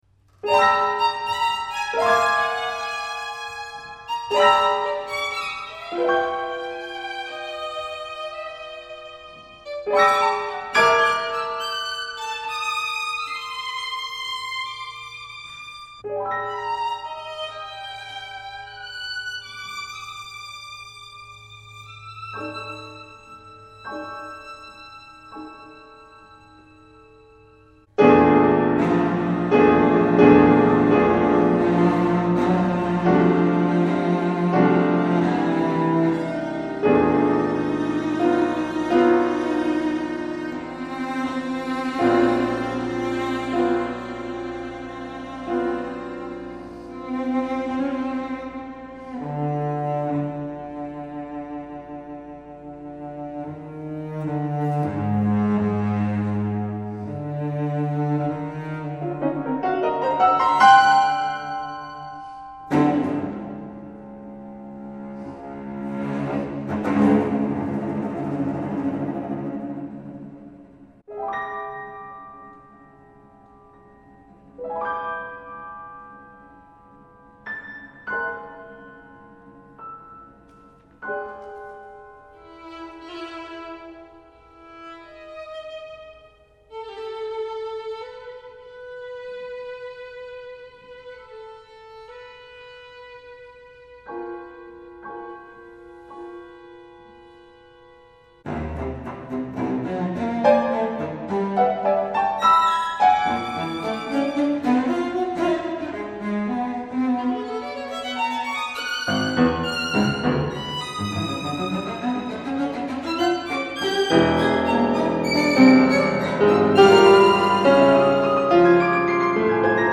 violin, Cello, Piano